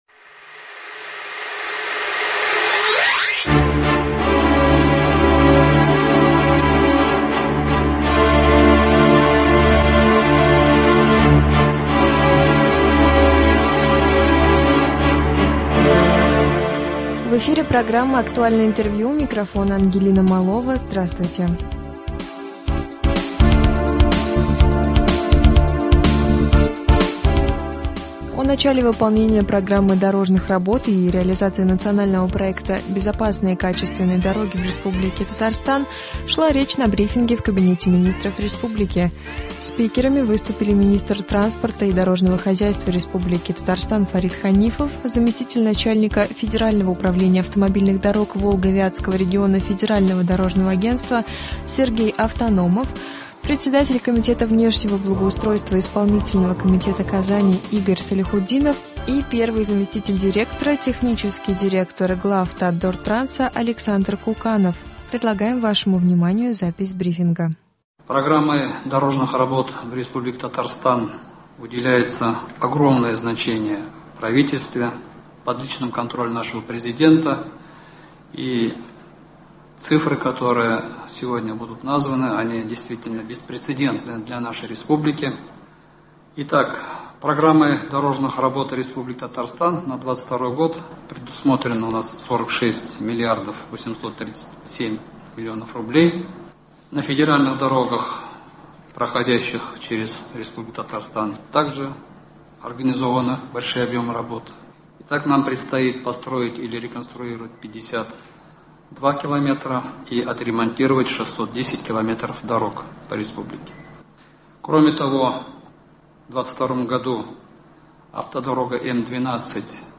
Актуальное интервью (11.05.22) | Вести Татарстан
О реализации программы дорожных работ в Татарстане рассказал министр транспорта и дорожного хозяйства РТ Фарит Ханифов на брифинге в Доме Правительства республики.